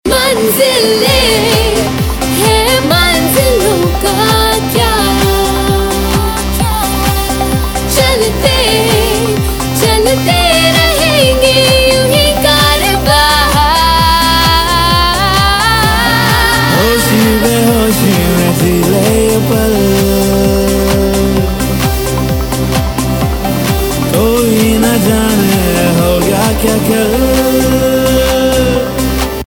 Indian POP Ringtones